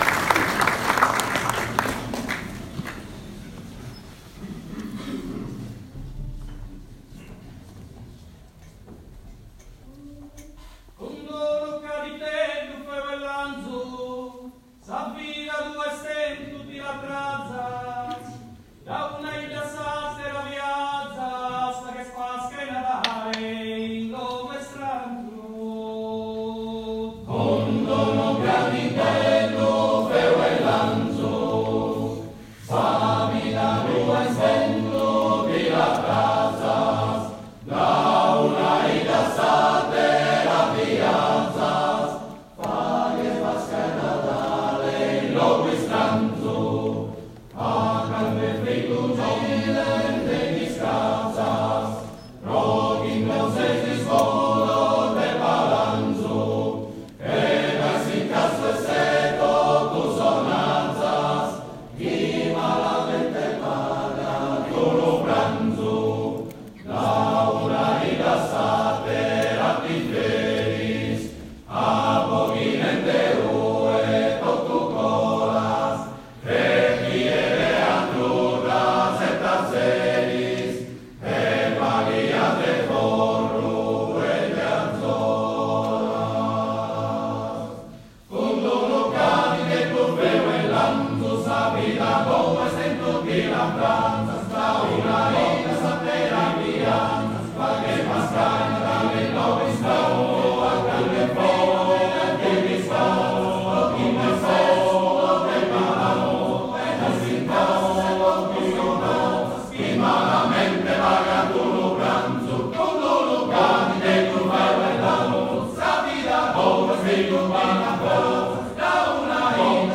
S'Arrodia - Coro Polifonico Maschile Sinnai - Repertorio